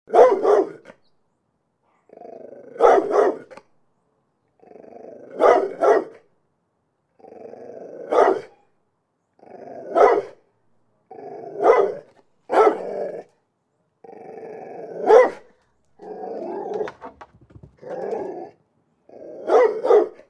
Другие рингтоны по запросу: | Теги: лай, Собака, пес